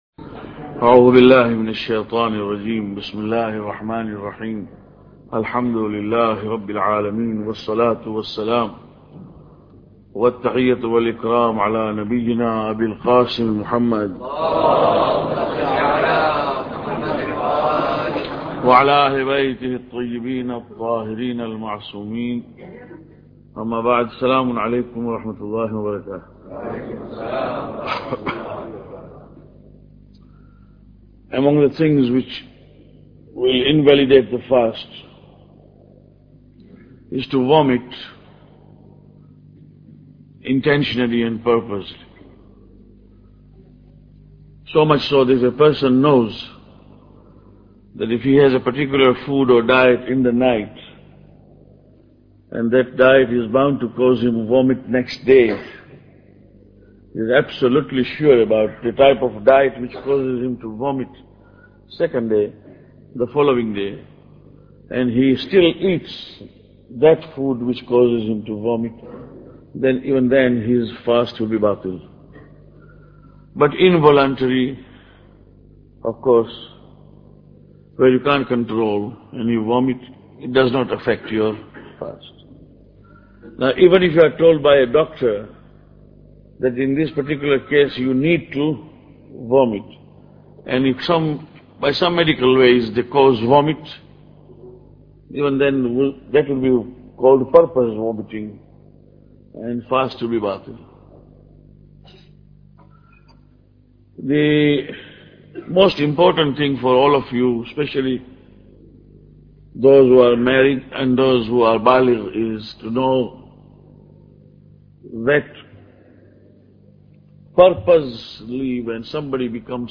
Lecture 9